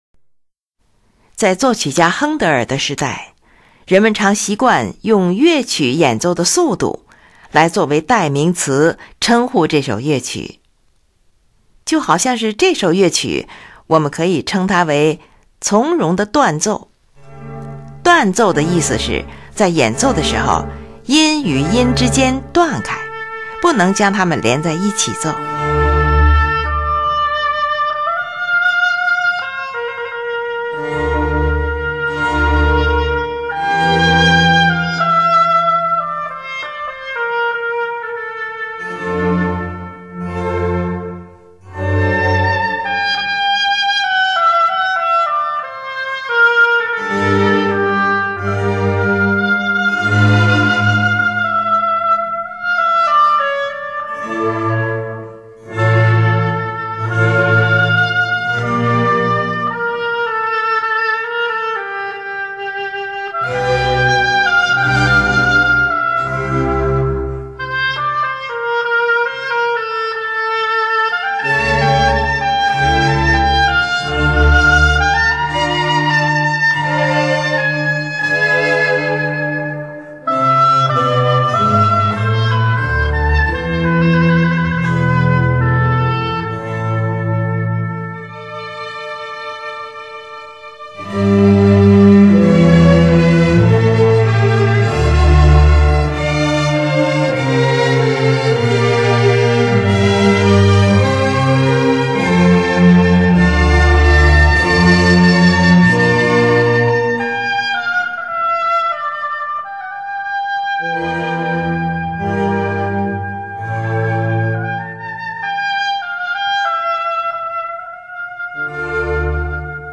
断奏的意思是，在演奏时，音与音之间断开，不能将它们连在一起奏。
乐器使用了小提琴、低音提琴、日耳曼横笛、法兰西横笛、双簧管、圆号、小号等